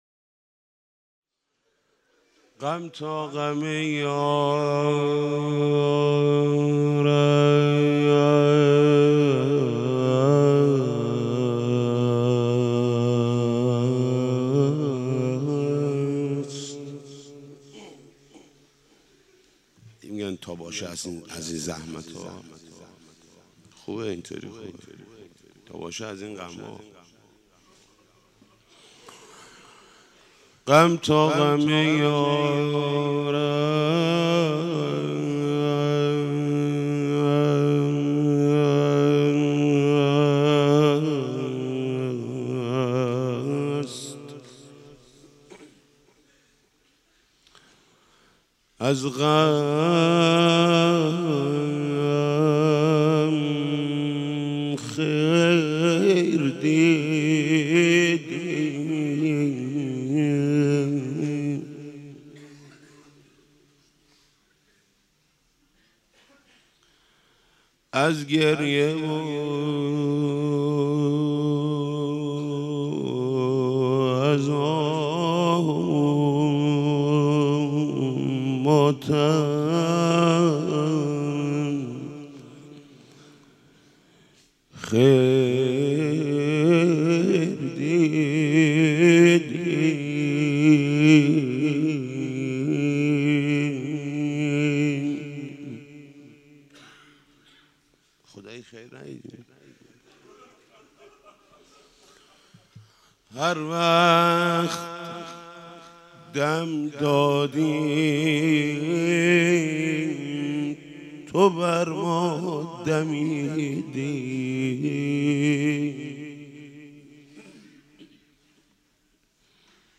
روضه.mp3